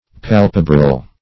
Search Result for " palpebral" : The Collaborative International Dictionary of English v.0.48: Palpebral \Pal"pe*bral\, a. [L. palpebralis, fr. palpebra: cf. F. palp['e]bral.] Of or pertaining to the eyelids.